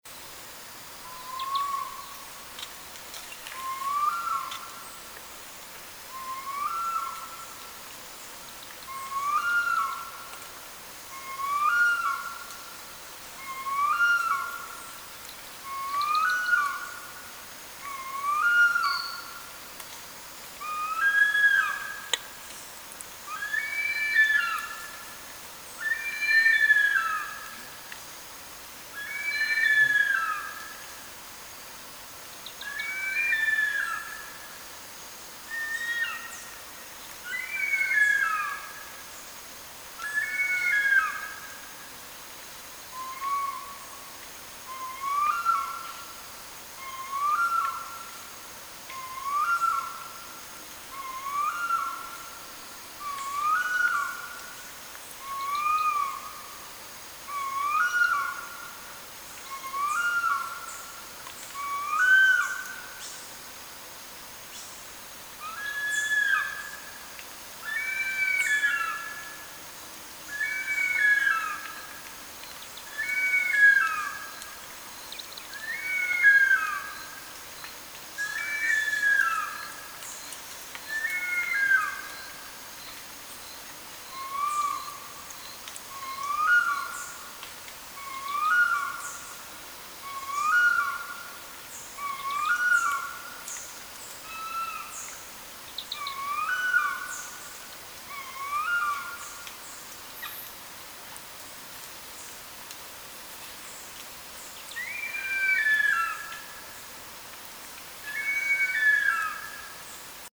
1. Harpy eagles (Harpia harpyja) audio recordings from WikiAves: